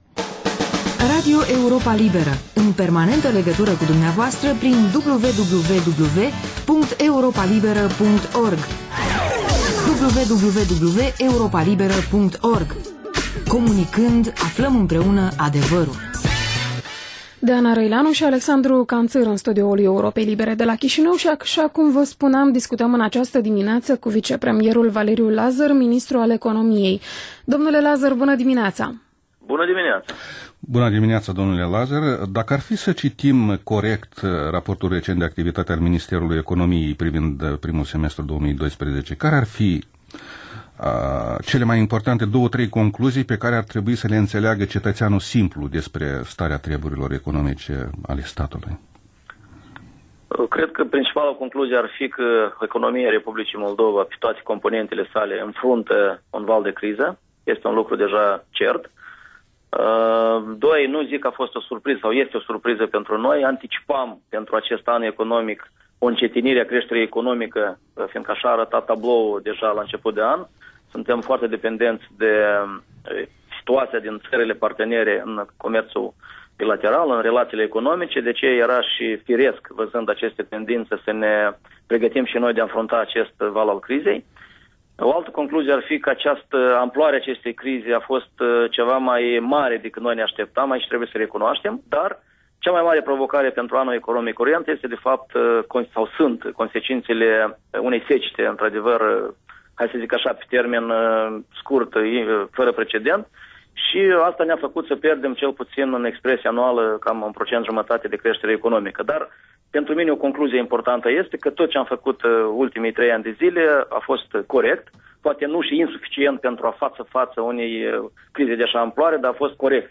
Interviu cu vicepremierul Valeriu Lazăr, ministrul economiei